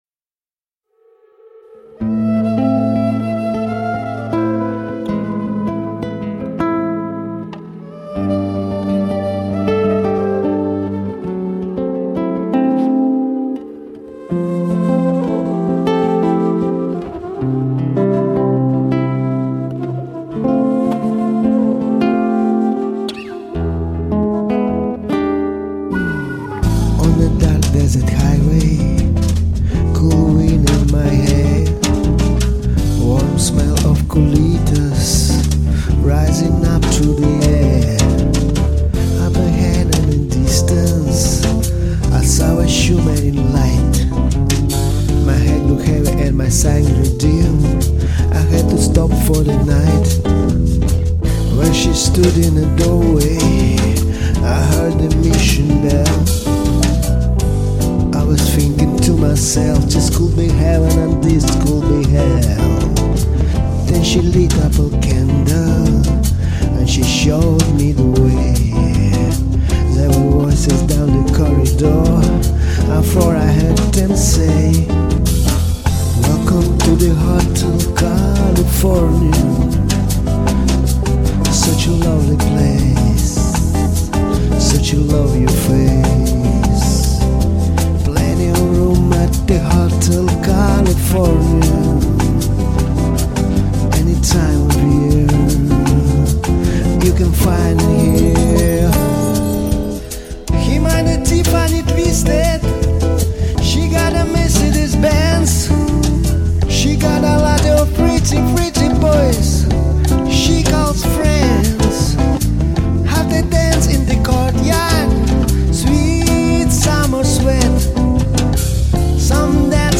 и такая же приятная, качающая аранжировка!